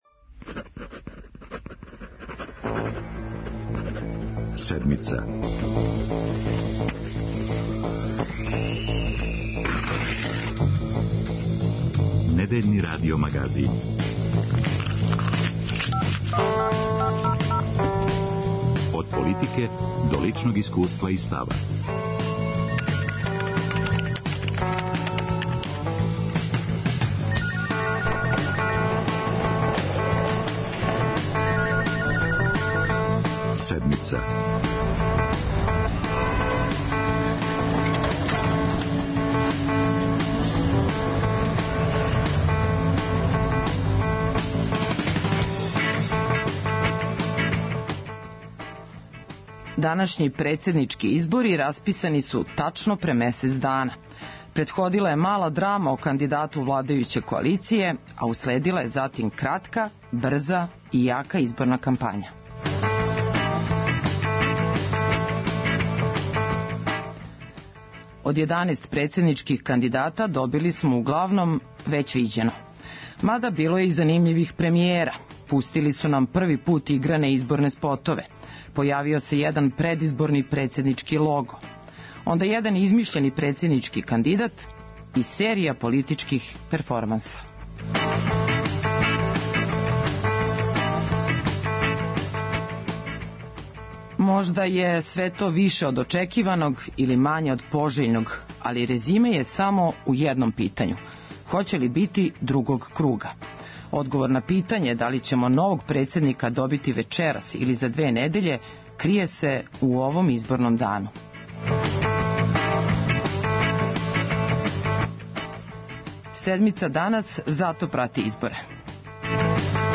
Ове недеље грађани бирају новог председника државе. У емисије говоре 'посматрачи' Седмице - репортери Радио Београда 1 широм Србије.